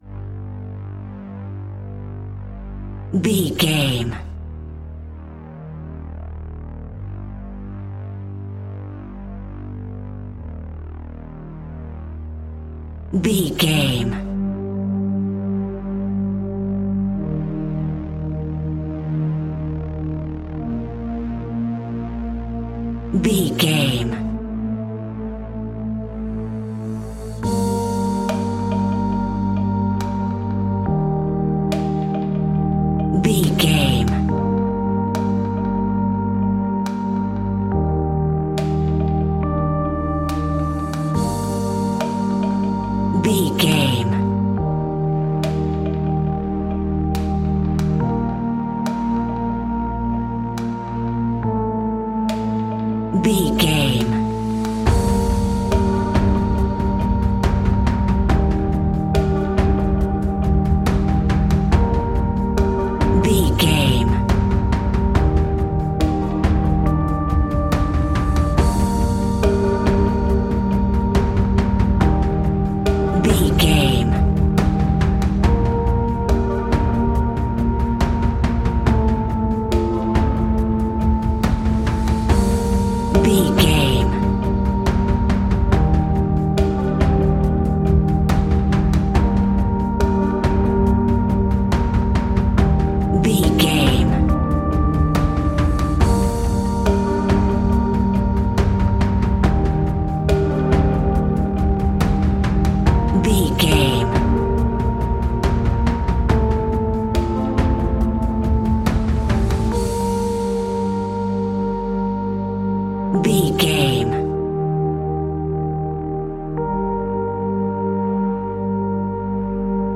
Aeolian/Minor
ominous
dark
haunting
eerie
electric piano
synthesiser
drums
instrumentals
horror music